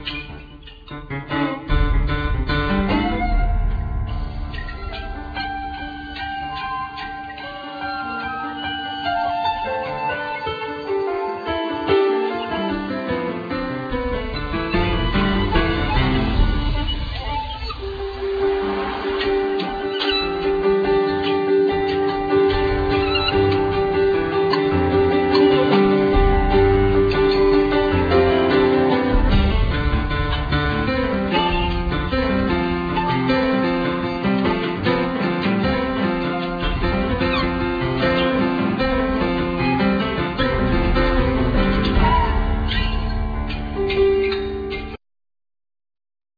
Vocals
Fiddle
Saxophone,Flute
Percussion
Bass
Piano,Keyboards